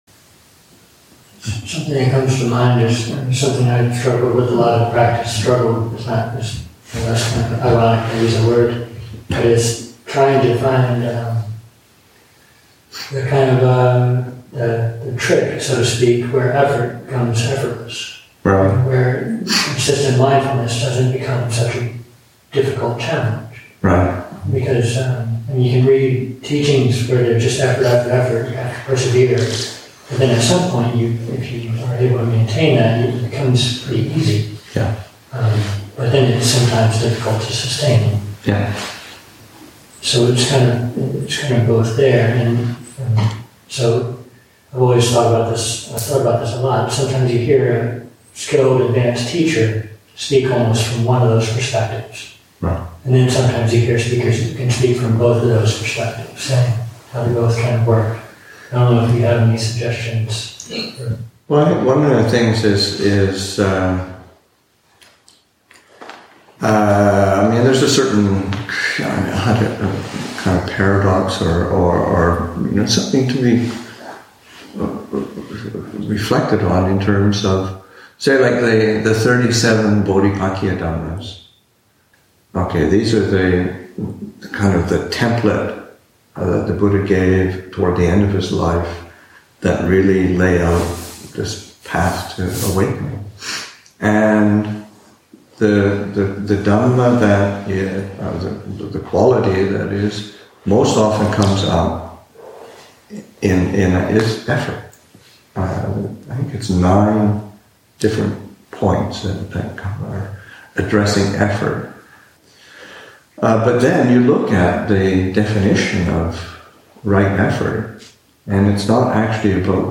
Western Disciples of the Thai Forest Tradition [2016], Session 53, Excerpt 6